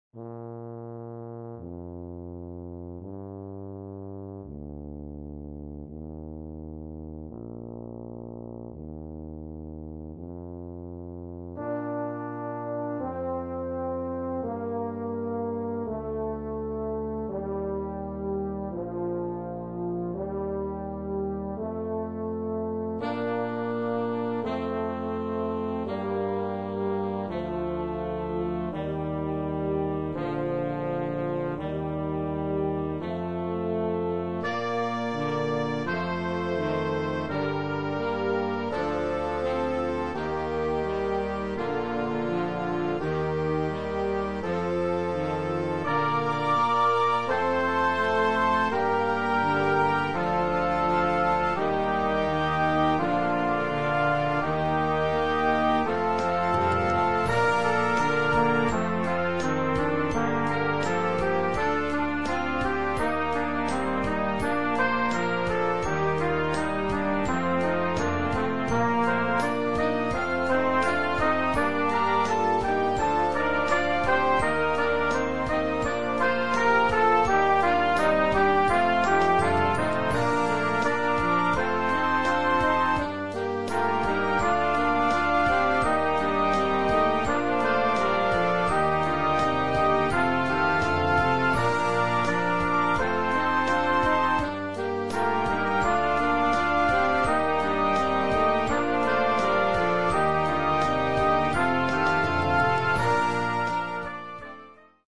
Klassieke arrangementen,